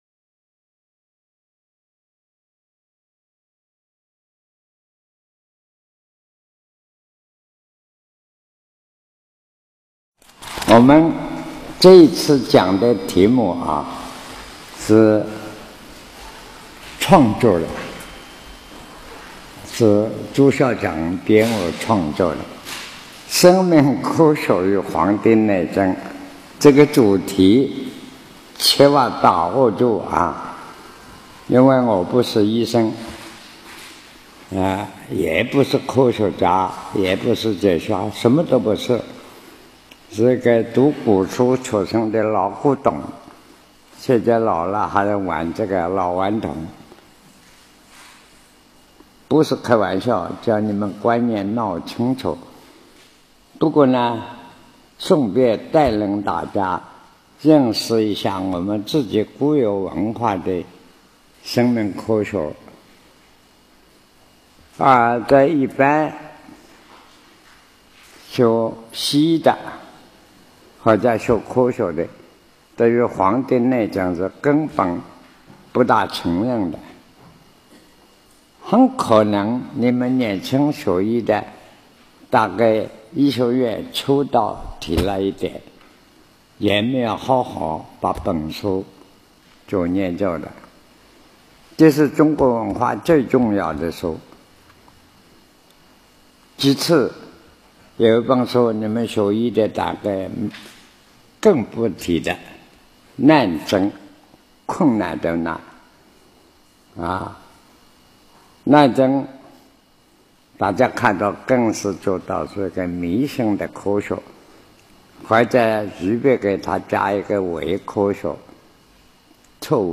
南怀瑾先生所讲《生命科学与黄帝内经》辅导学习课程